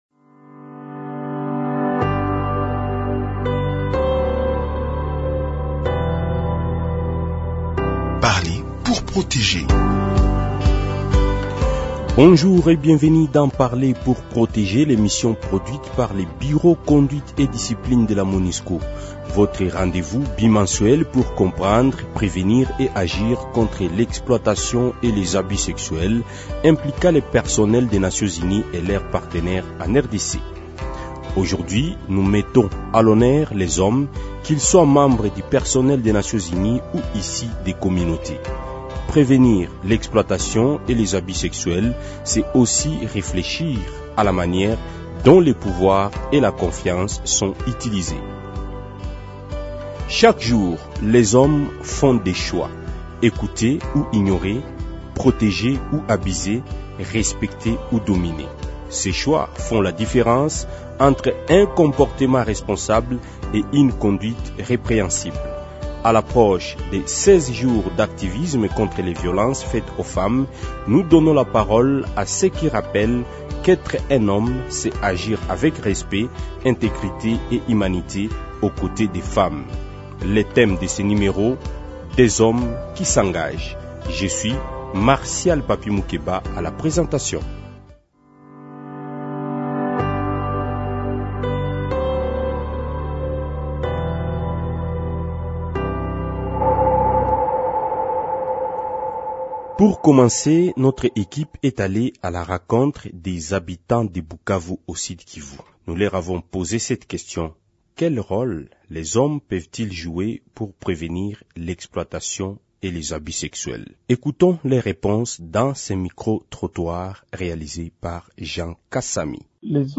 Le rôle des hommes dans la prévention Pour comprendre la perception des communautés, l’équipe de l’émission s’est rendue à Bukavu, au Sud-Kivu, où elle a échangé avec des habitants et des leaders locaux.